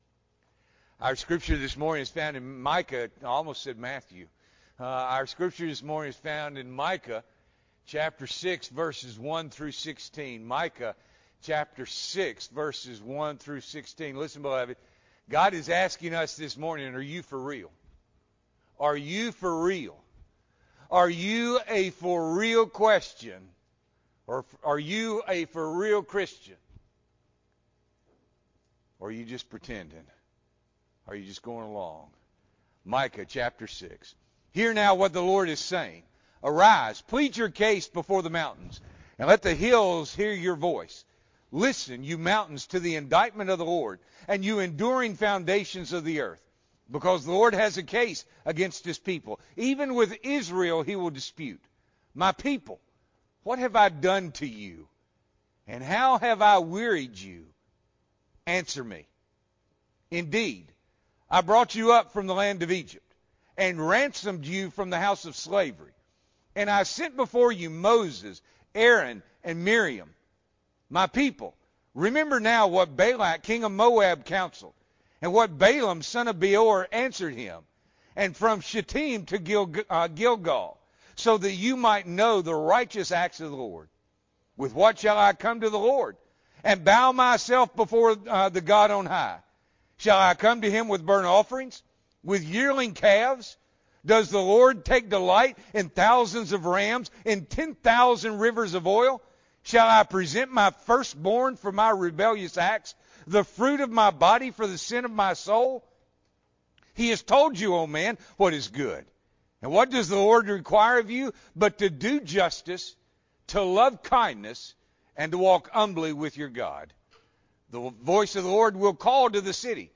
June 13, 2021 – Morning Worship